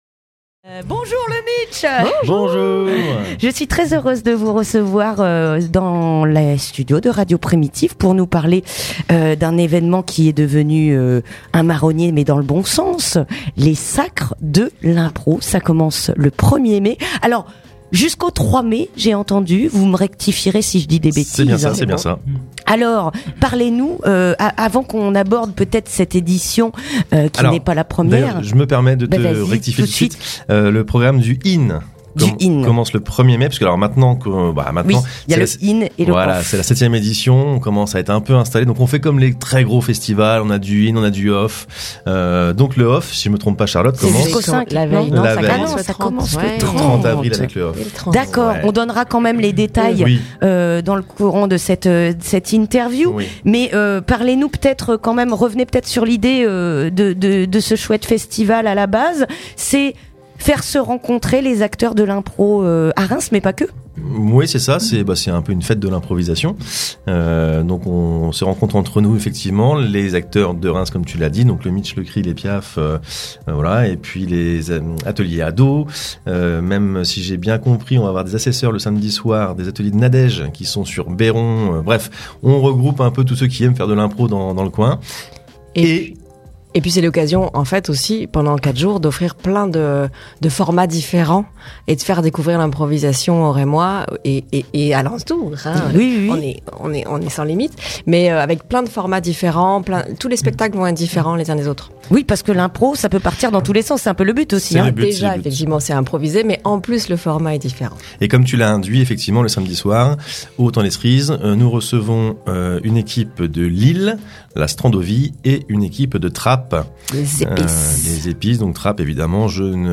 Interview du Mitch (15:59)